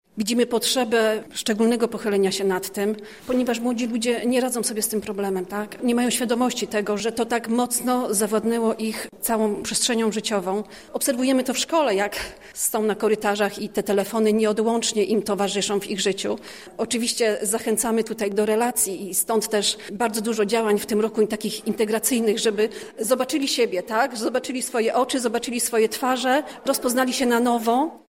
Konferencję poświęconą behawioralnym uzależnieniom wśród dzieci i młodzieży zorganizował Regionalny Ośrodek Polityki Społecznej.